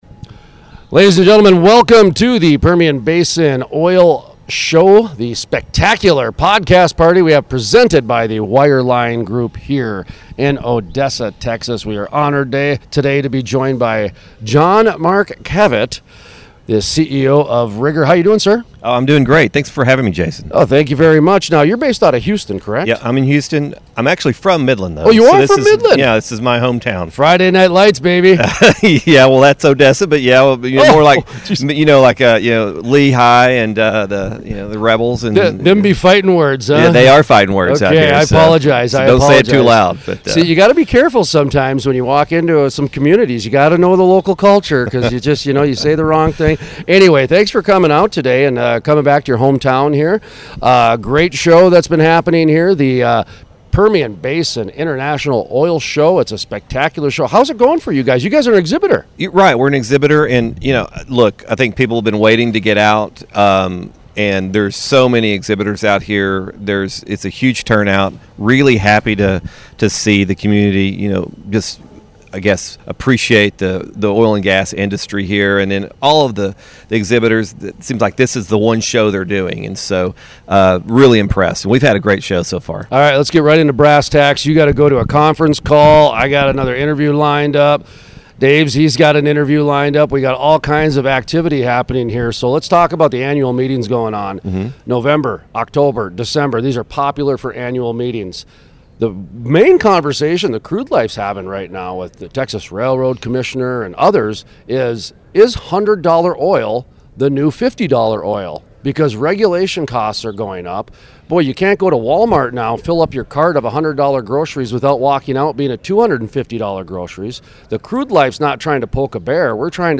interviewed
at the PBIOS Podcast Party at the Permian Basin International Oil Show (PBIOS) in Odessa, Texas